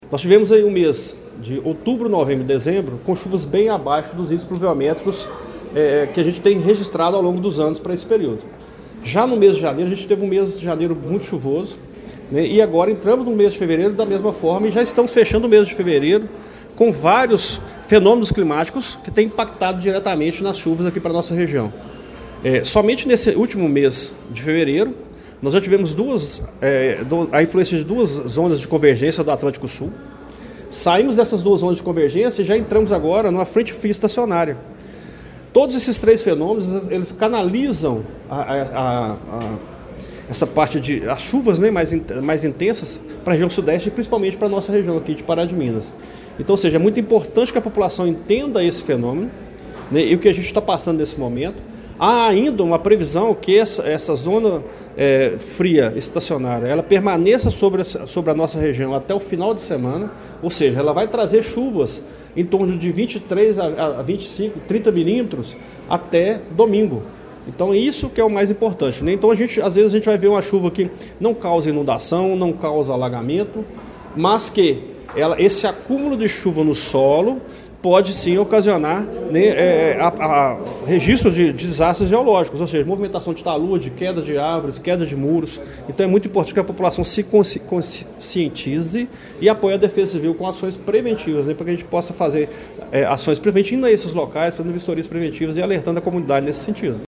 O coordenador de Proteção e Defesa Civil, Edson Cecílio da Silva, apresentou uma análise detalhada do cenário climático esperado para os próximos dias em Pará de Minas, durante coletiva de imprensa realizada na tarde de ontem (24).